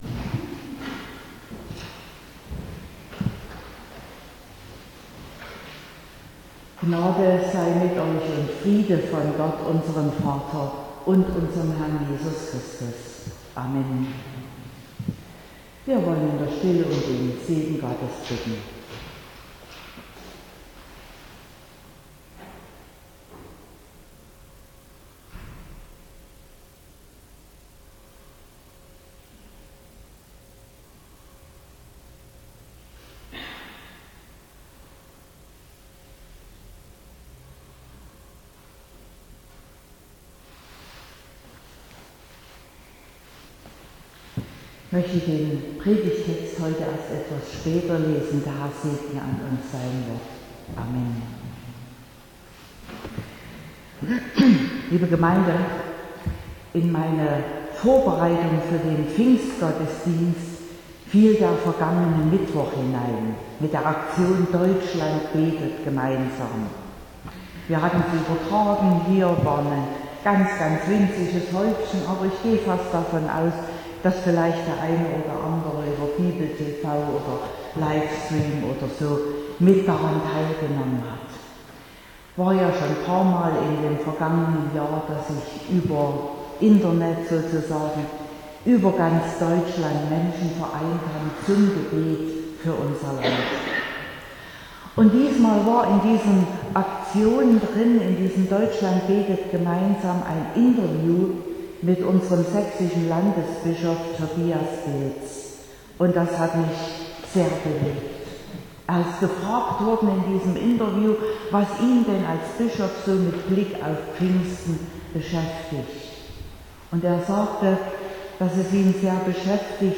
24.05.2021 – Gottesdienst
Predigt (Audio): 2021-05-24_Entspannte_Arbeitsfreude_durch_die_Gaben_des_Heiligen_Geistes.mp3 (27,5 MB)